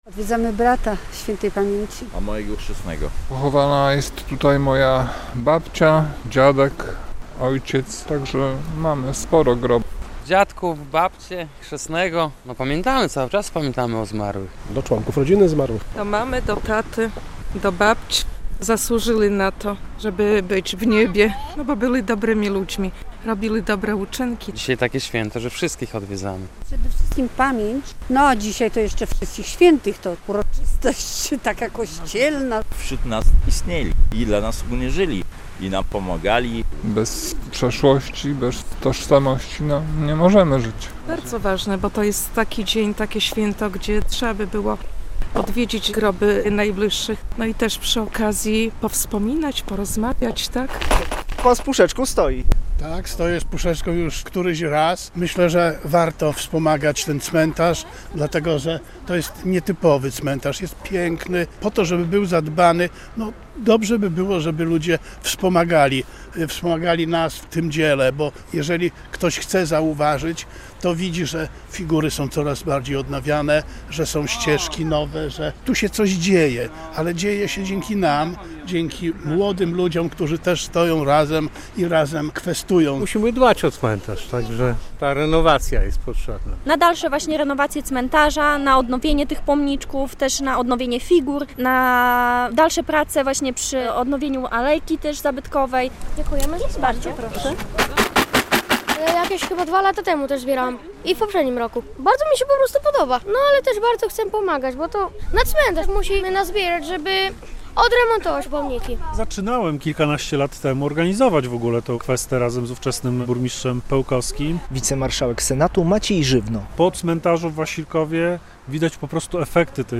Mieszkańcy regionu odwiedzają cmentarze - na wielu nekropoliach prowadzone są też kwesty - relacja